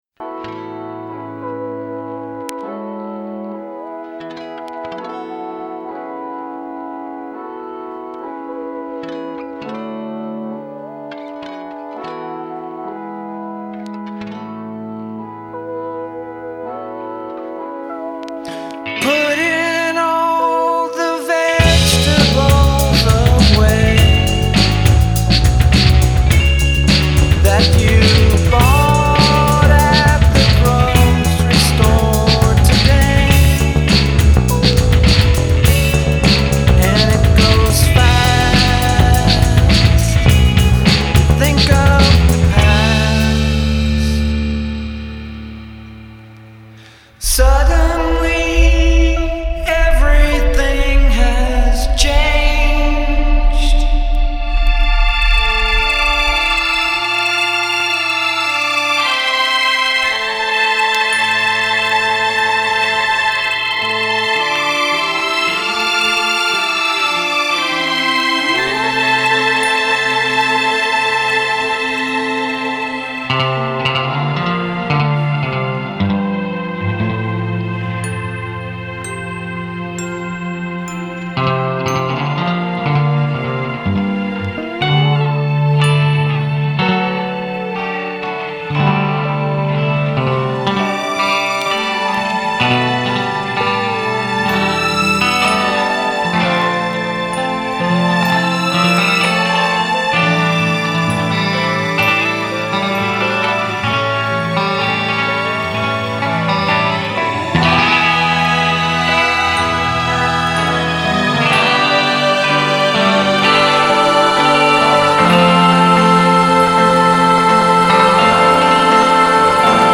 Жанр: neo-psychedelia, art rock, dream pop